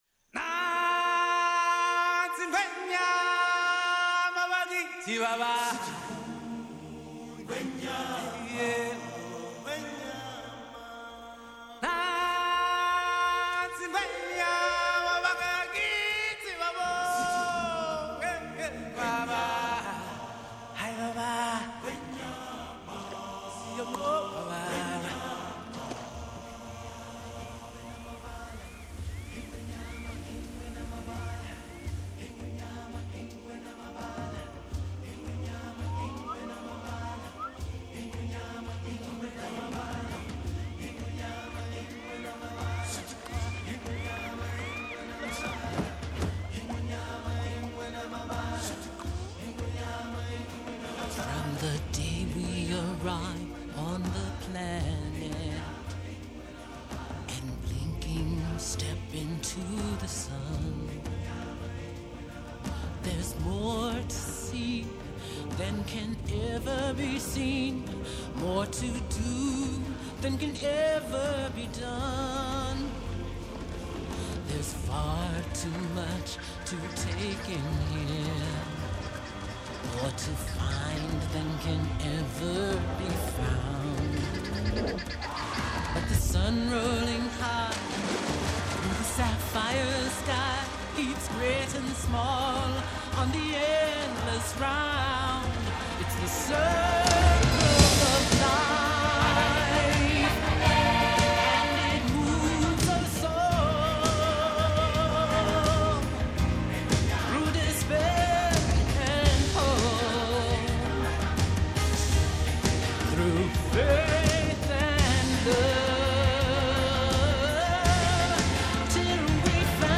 Soundtrack, Pop